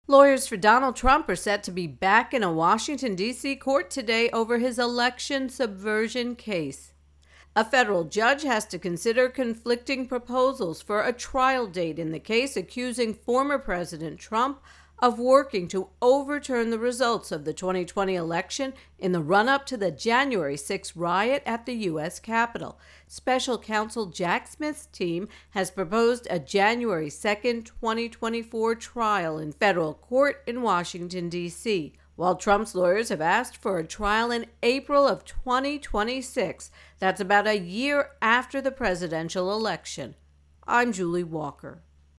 reports on Trump Indictment Capitol Riot